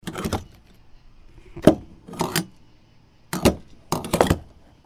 attachpump.wav